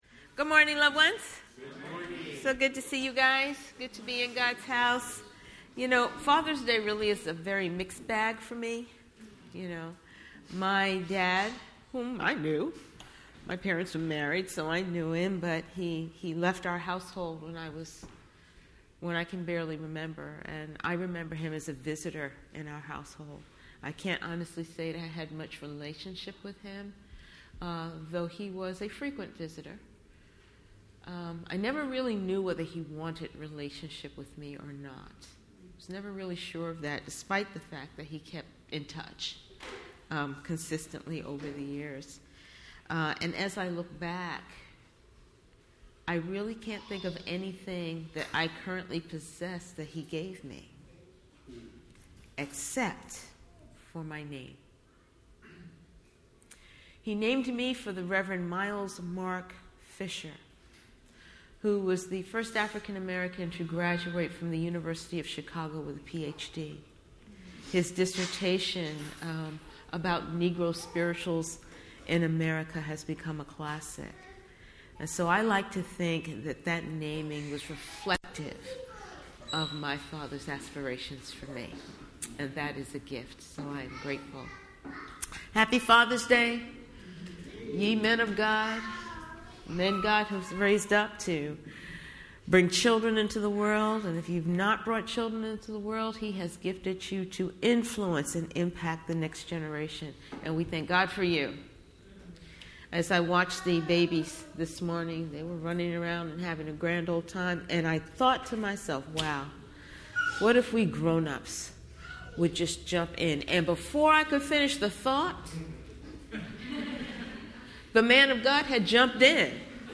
Getting Our Acts Together Passage: Acts 5:1-16 Service Type: Sunday Morning Related « Simple Solutions to Simple Problems